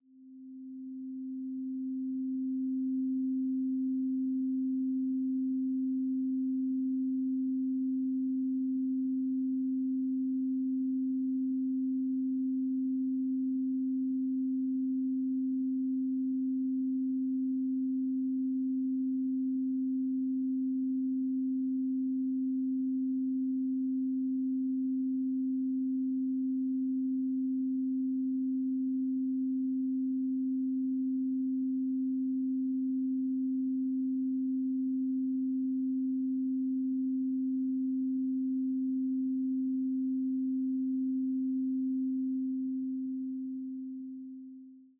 🎹 Classical Piano Collection
Beautiful piano pieces inspired by the great composers.
Duration: 0:45 · Genre: Baroque · 128kbps MP3